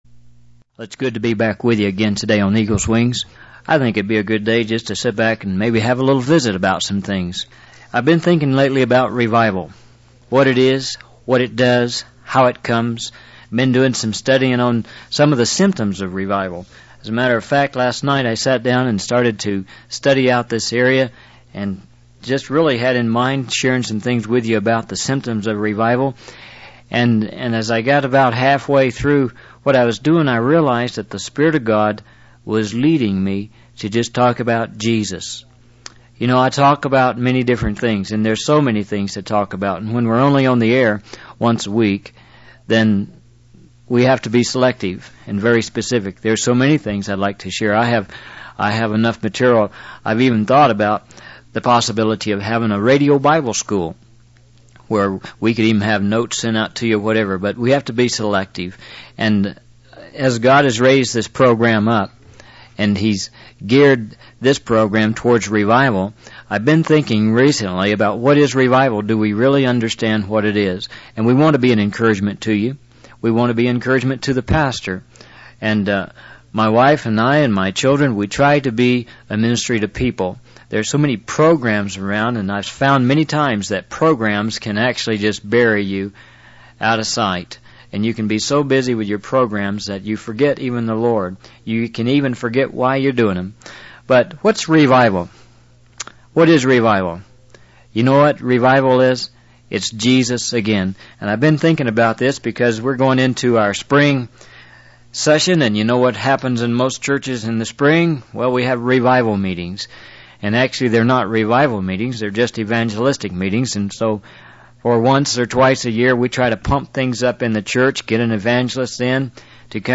In this sermon, the speaker shares his personal journey of seeking God and experiencing revival. He emphasizes the importance of desperation and setting one's face unto the Lord God.